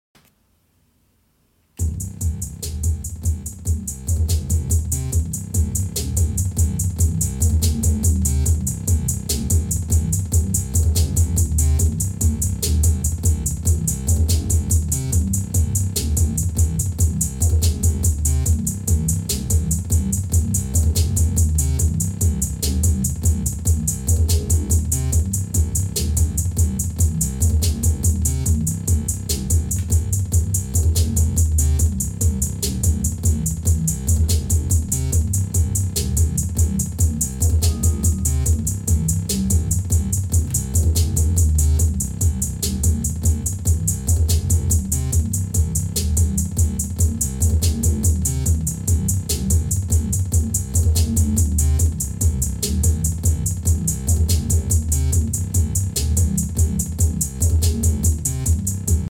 Echo And Reverb Sound Effects Free Download